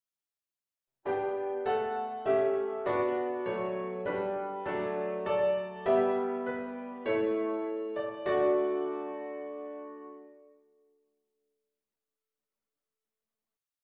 a. een typische slothemiool: Door de vorm van de melodie, maar vooral door het harmonisch ritme verandert de 3/4-maat tijdelijk in een (waargenomen) 3/2-maat;
a. voorbeeld van een slothemiool